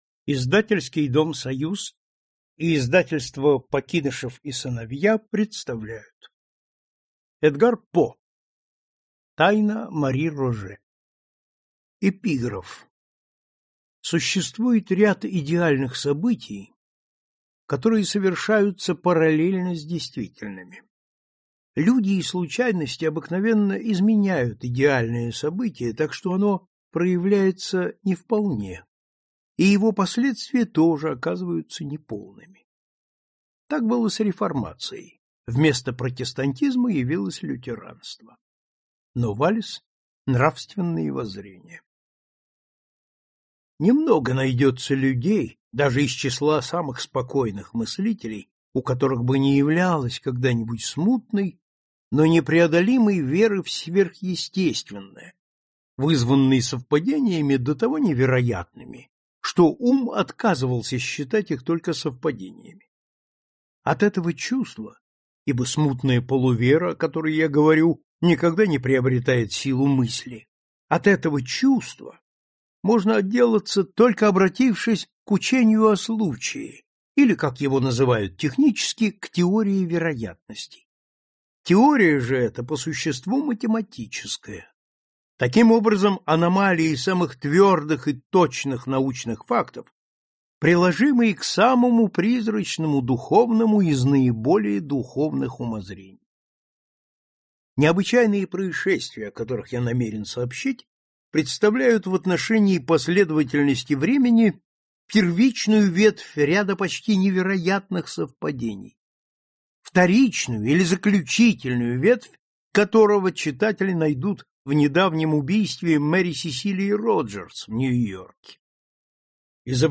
Аудиокнига Тайна Мари Роже | Библиотека аудиокниг